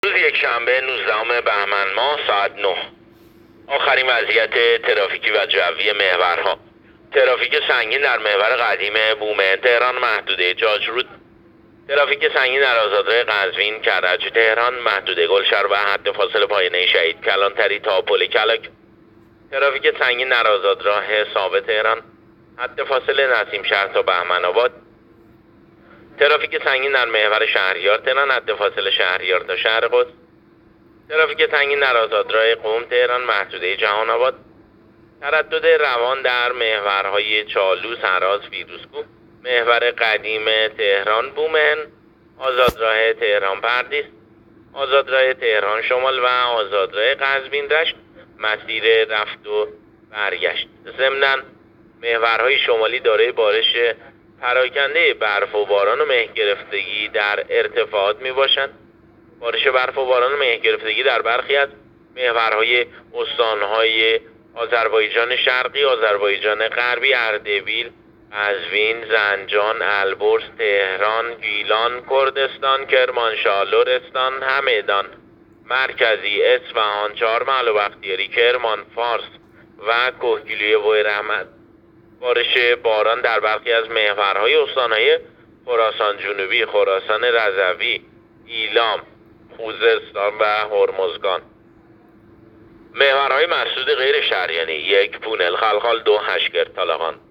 گزارش رادیو اینترنتی از آخرین وضعیت ترافیکی جاده‌ها ساعت ۹ نوزدهم بهمن؛